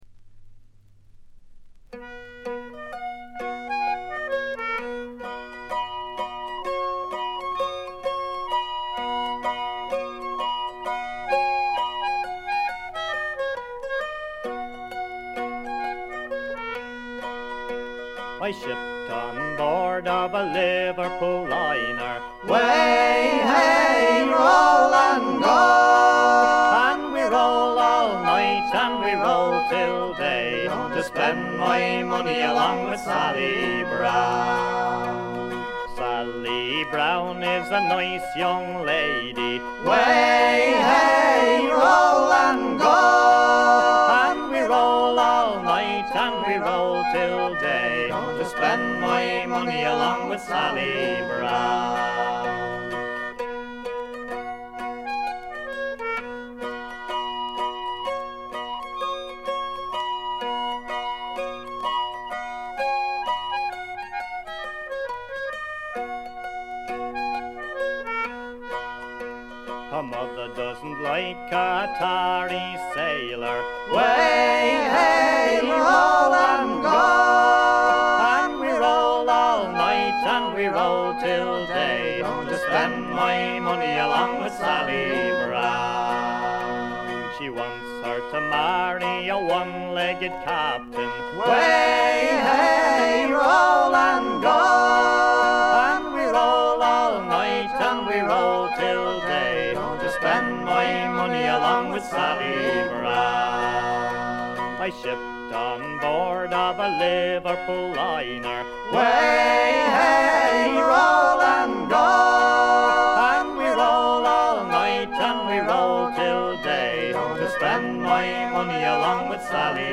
試聴曲は現品からの取り込み音源です。
Vocals, Bouzouki, Tin Whistle
Vocals, Guitar, Banjo, Concertina
Vocals, Mandolin, Harmonica, Guitar